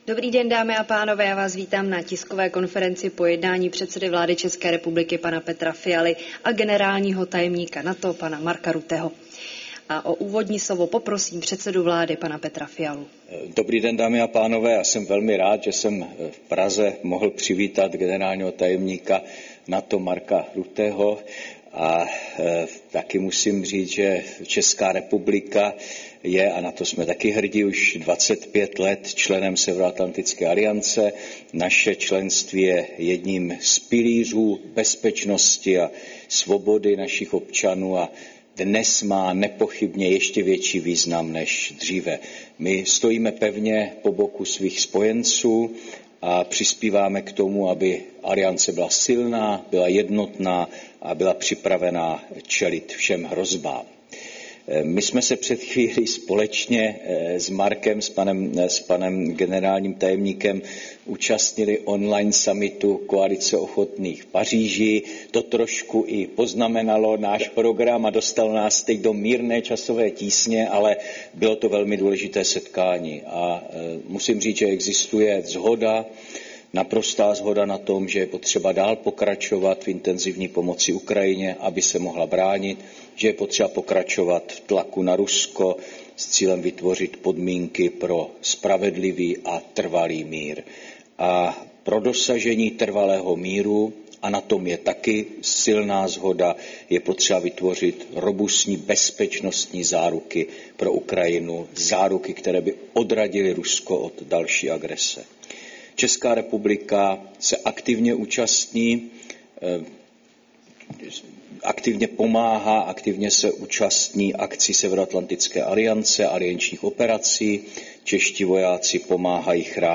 Tisková konference po jednání premiéra Petra Fialy s generálním tajemníkem NATO Markem Ruttem